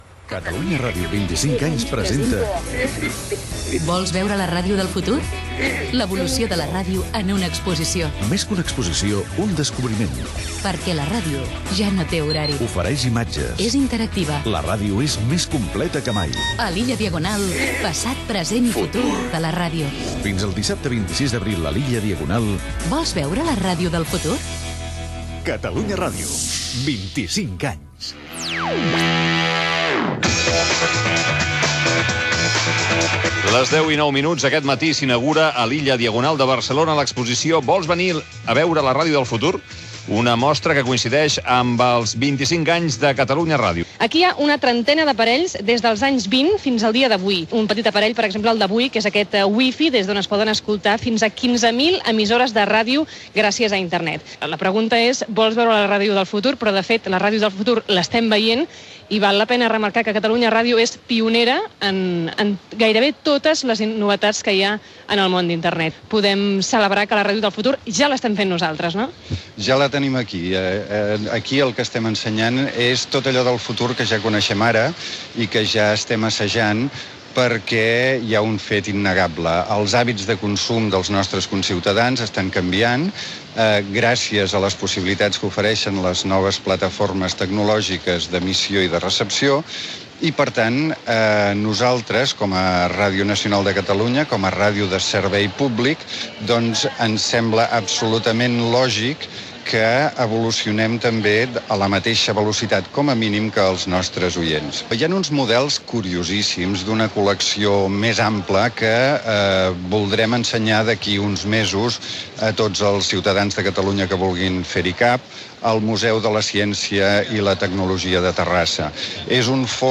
Informació de l'exposició "Vols veure la ràdio del futur?" a l'Illa Diagonal de Barcelona, amb una entrevista
Info-entreteniment